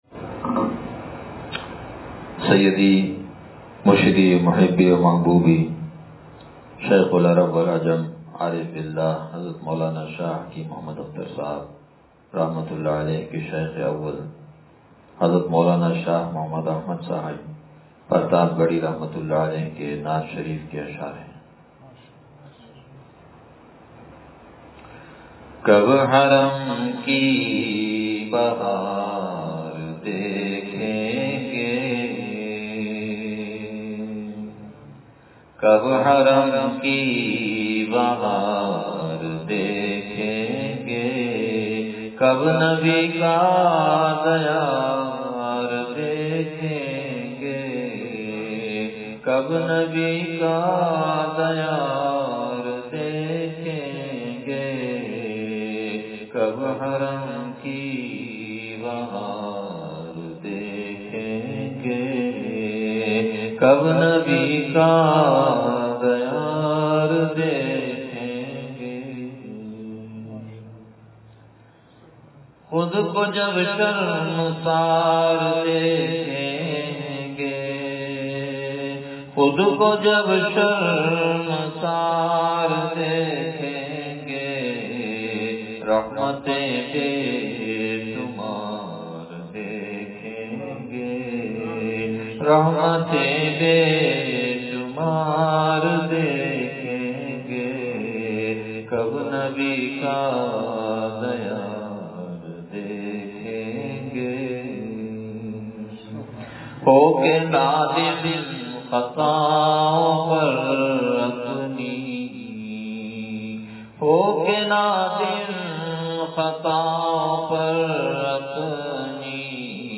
نعت شریف – ایمان کی لذت تجھے اللہ چکھائے – دنیا کی حقیقت – نشر الطیب فی ذکر النبی الحبیب صلی اللہ علیہ وسلم